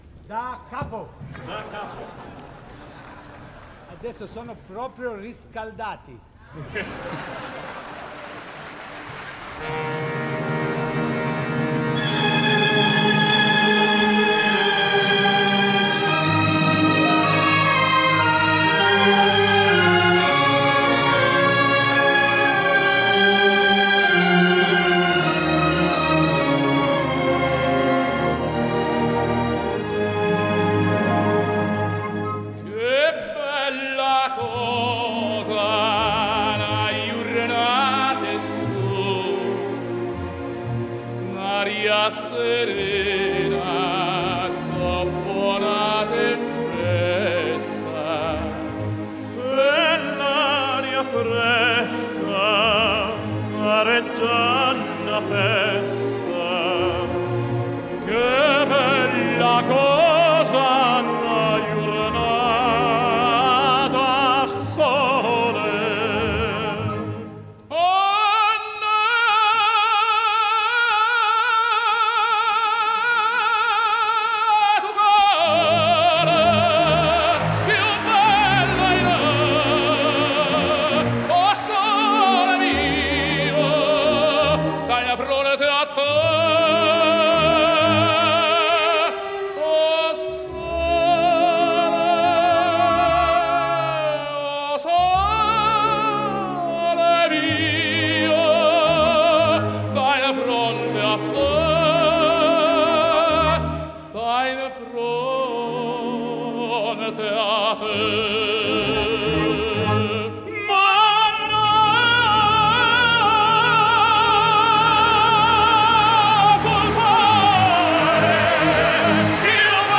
[2007-3-5]《我的太阳》三大男高音比拼高音C 激动社区，陪你一起慢慢变老！